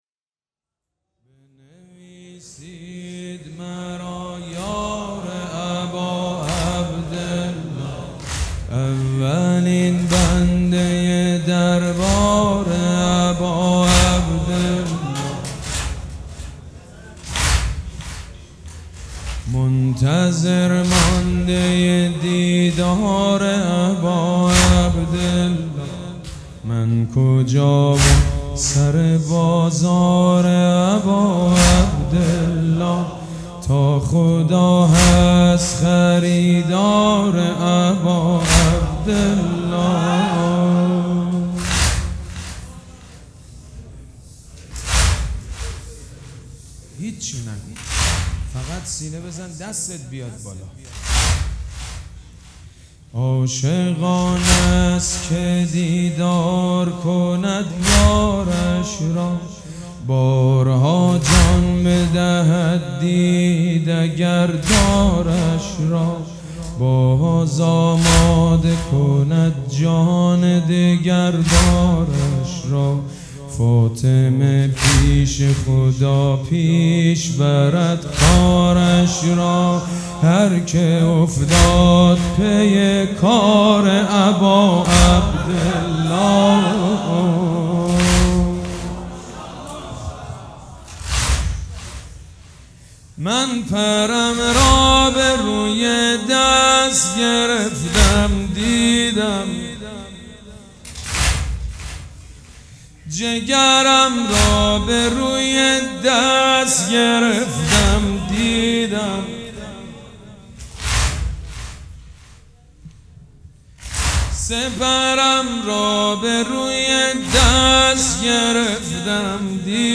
سید مجید بنی فاطمه